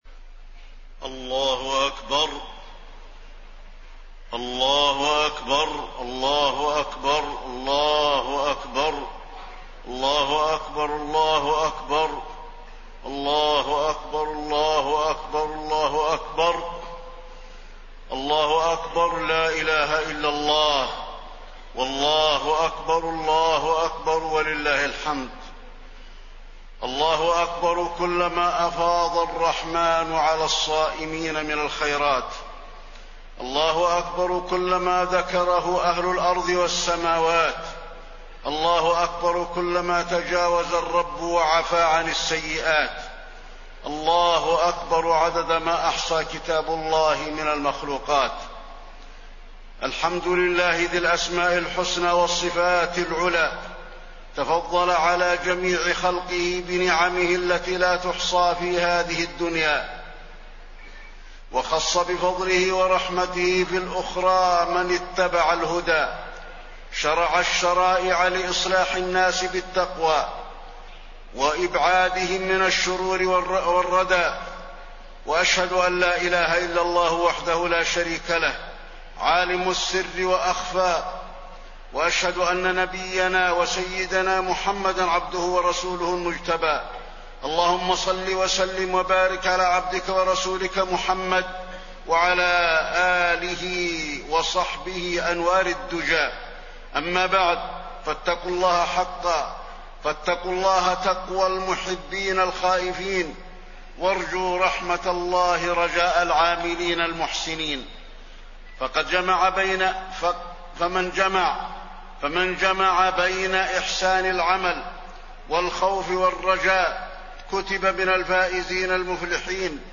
خطبة عيد الفطر - المدينة - الشيخ علي الحذيفي
تاريخ النشر ١ شوال ١٤٣١ هـ المكان: المسجد النبوي الشيخ: فضيلة الشيخ د. علي بن عبدالرحمن الحذيفي فضيلة الشيخ د. علي بن عبدالرحمن الحذيفي خطبة عيد الفطر - المدينة - الشيخ علي الحذيفي